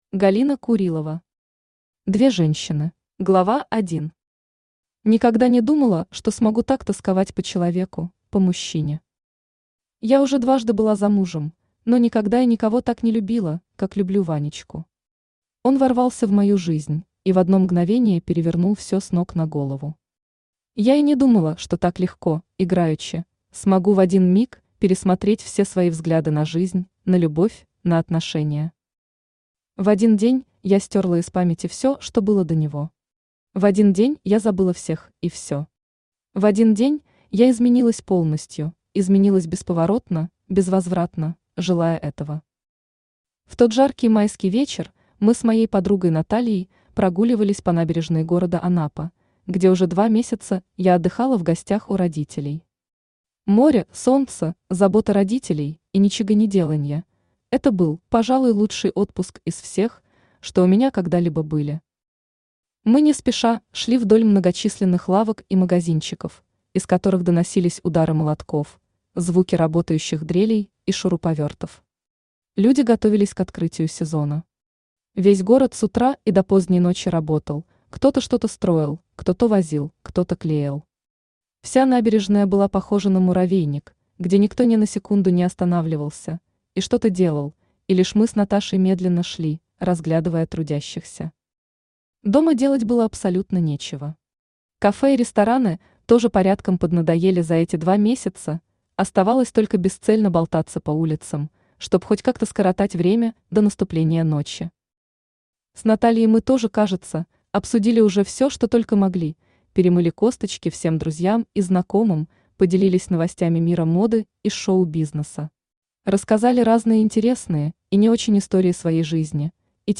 Аудиокнига Две женщины | Библиотека аудиокниг
Aудиокнига Две женщины Автор Галина Евгеньевна Курилова Читает аудиокнигу Авточтец ЛитРес.